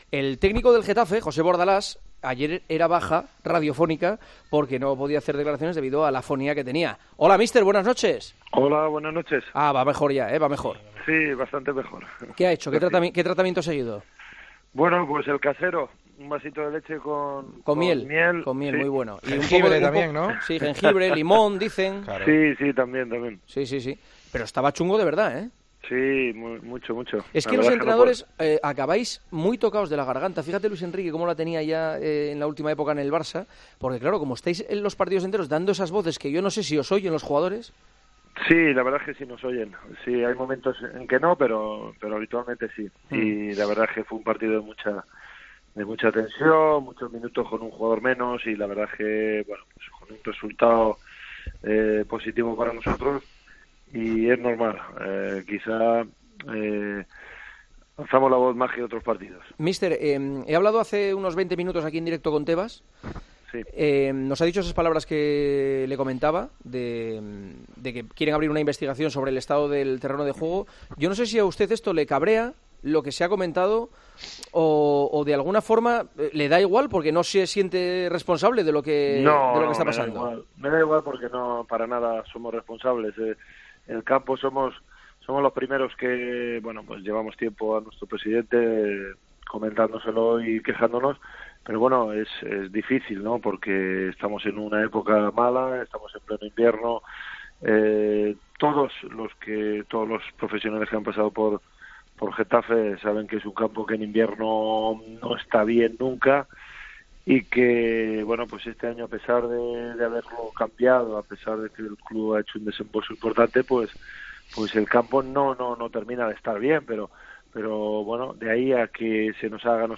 Juanma Castaño entrevistó al técnico del Getafe, después del anuncio de Javier Tebas en El Partidazo de COPE que quiere investigar el césped del...